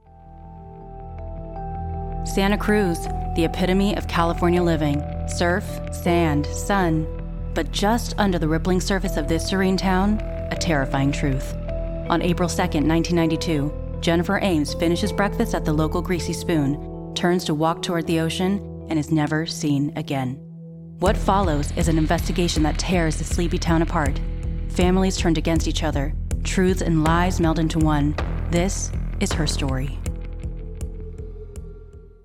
expressive female voice talent
True Crime Podcast Intro